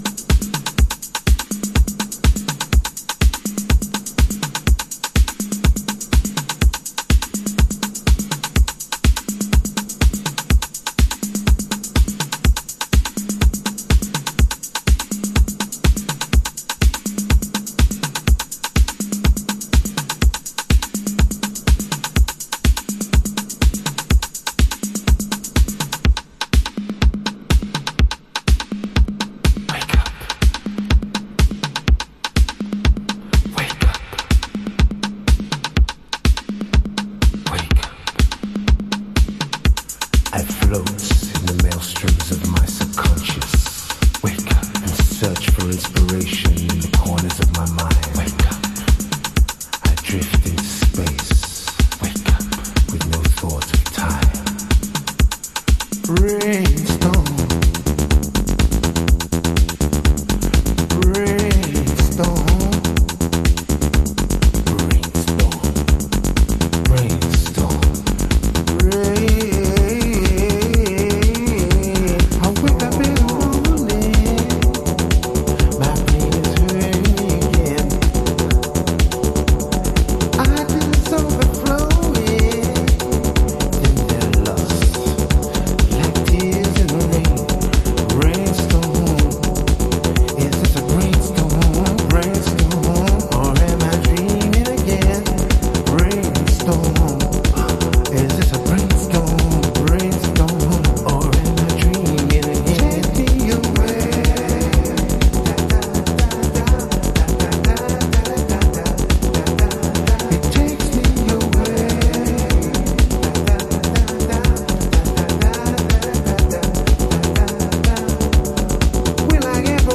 Chicago Oldschool / CDH
ザ・ヴォーカルハウス
今こんな胸キュン・ヴォーカルハウスなかなか無いですよね。
Vox Original